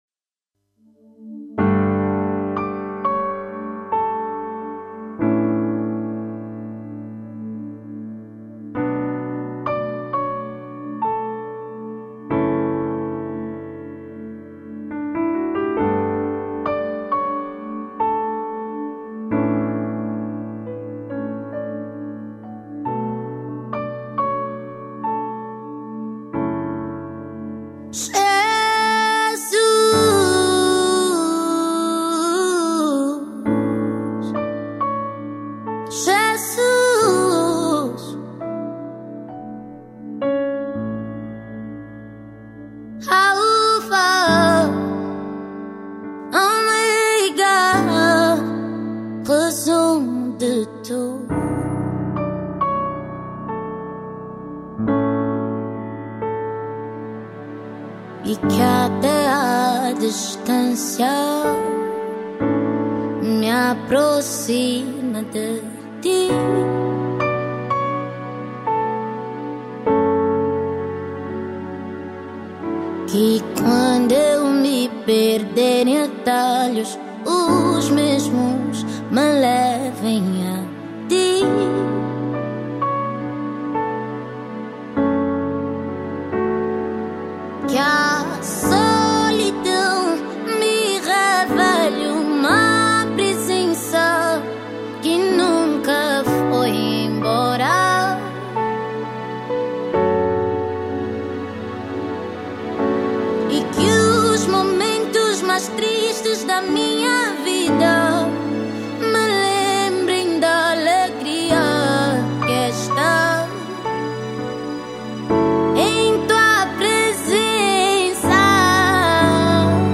Gospel 2024